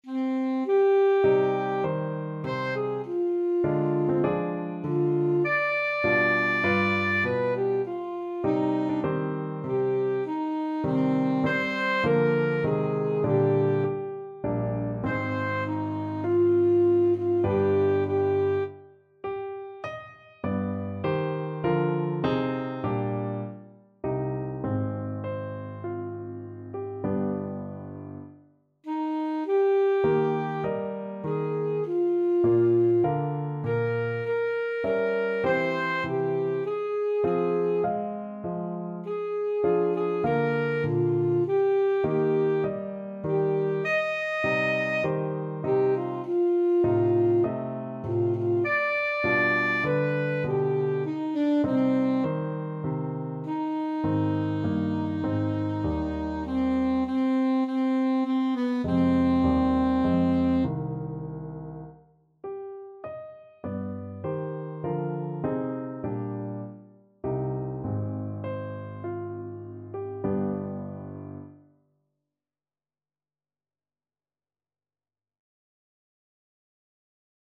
Alto Saxophone
C minor (Sounding Pitch) A minor (Alto Saxophone in Eb) (View more C minor Music for Saxophone )
4/4 (View more 4/4 Music)
Largo =c.100
Classical (View more Classical Saxophone Music)